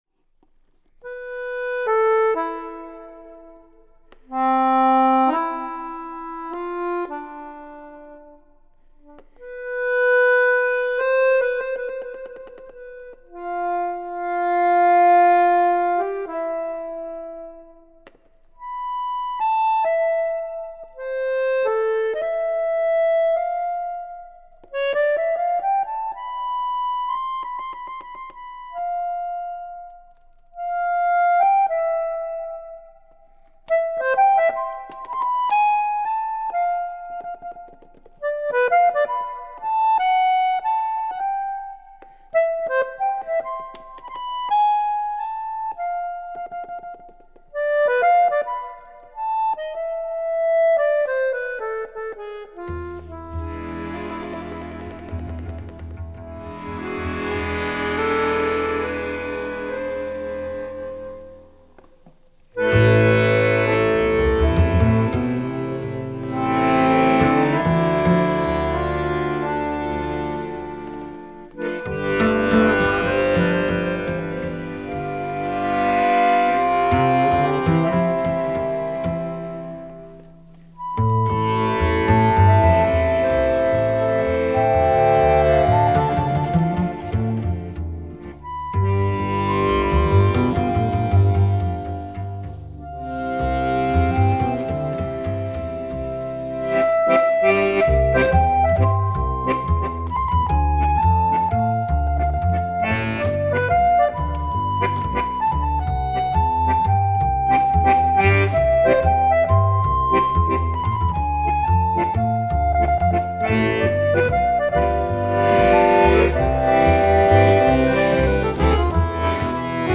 ki so mešanica folk glasbe, jazza in klasične glasbe.